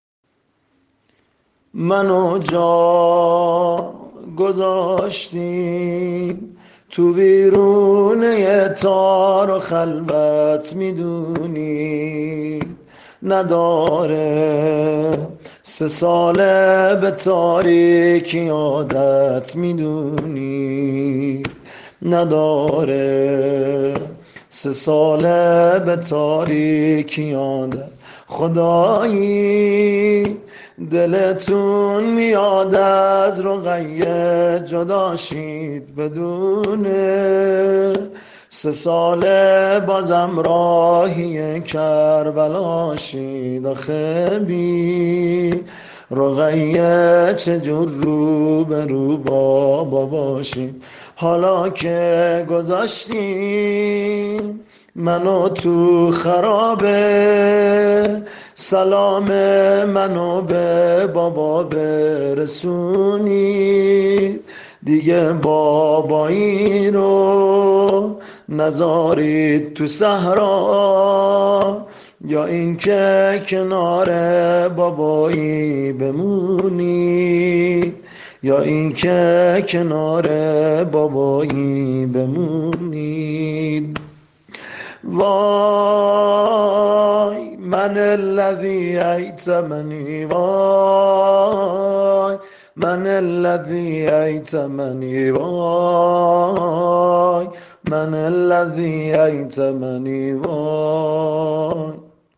هم حزین هم قشنگ و احساسی پنج شنبه 9 آبان 1398ساعت : 20:25